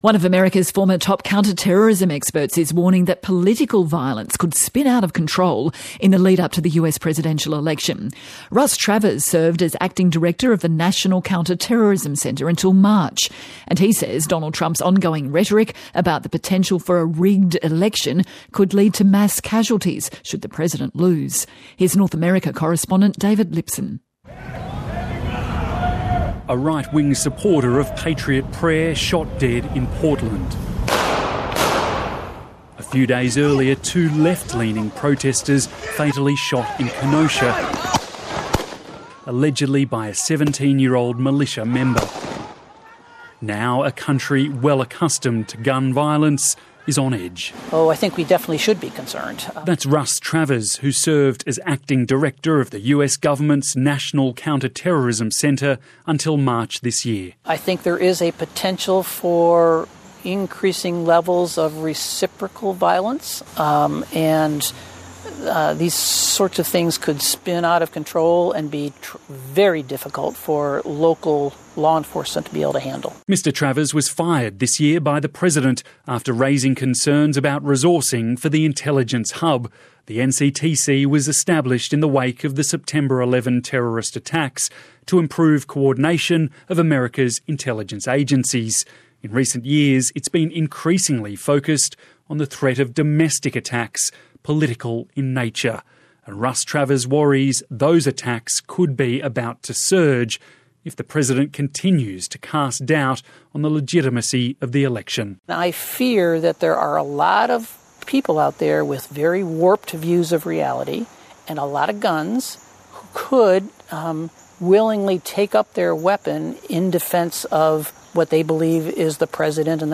Russ Travers, former Acting Director, US Government's National Counter-Terrorism Centre